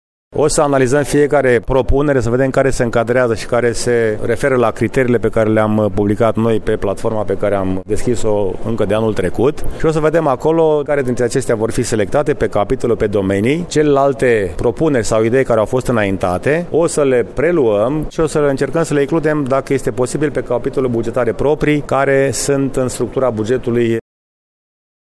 Primarul municipiului Brașov, George Scripcaru.